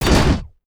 IMPACT_Generic_17_mono.wav